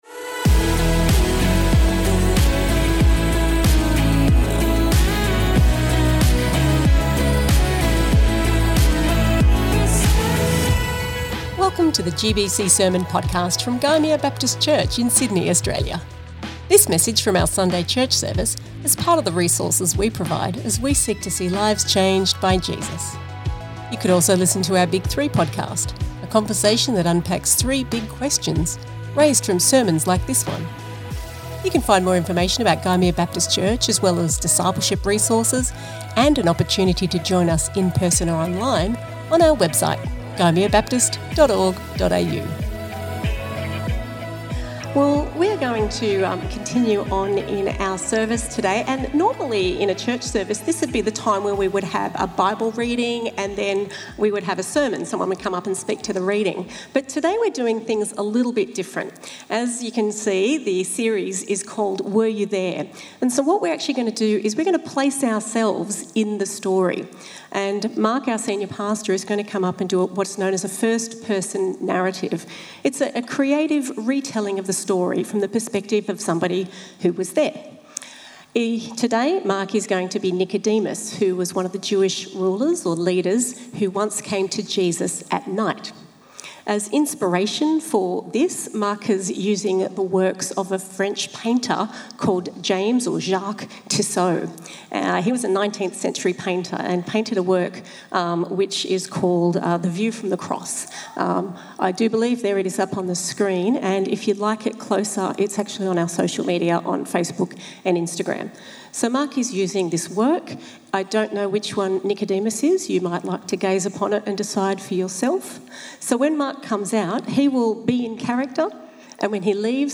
This message from our Sunday church service is part of the resources we provide as we seek to see lives changed by Jesus.